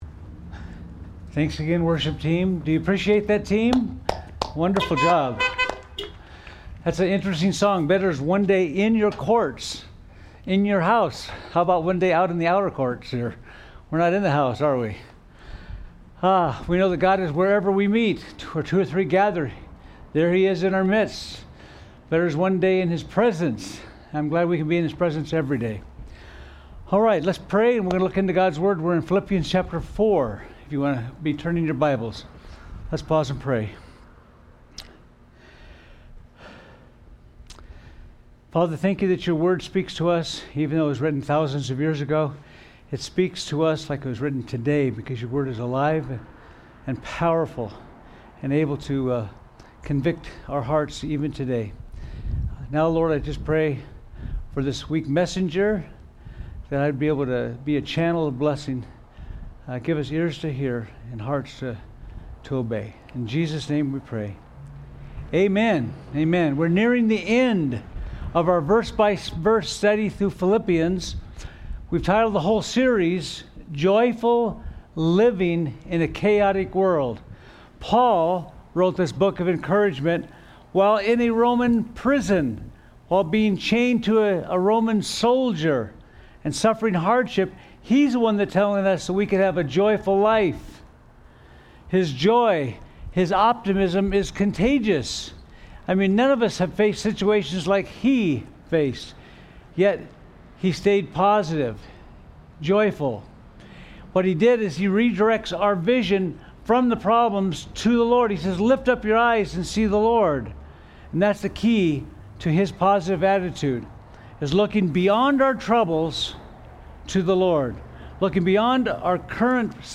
Sermons – Twin Peaks Community Church
Drive-In Service